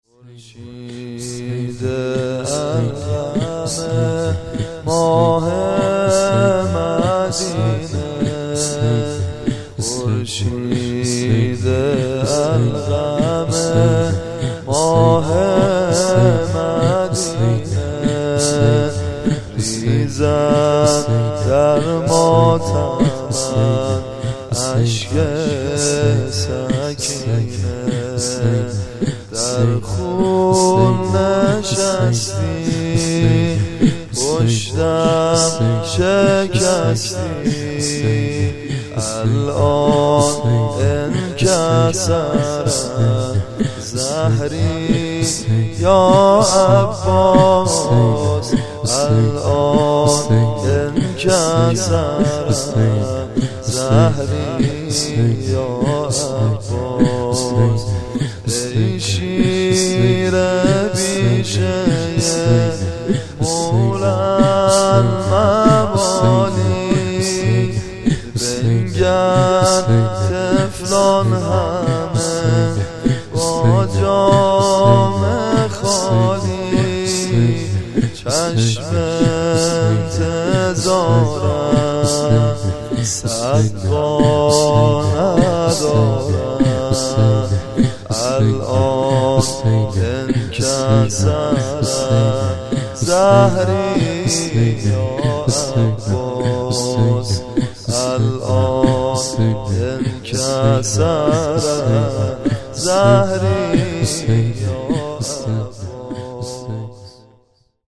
در استودیوی عقیق